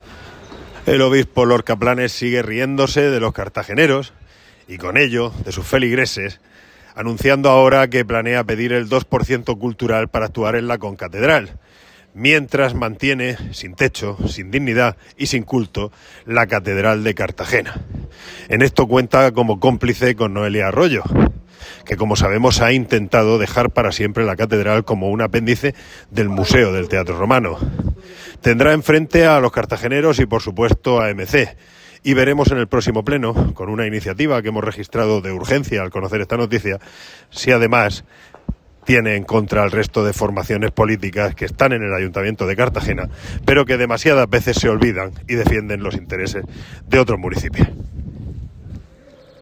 Declaraciones de Jesús Giménez, MC Cartagena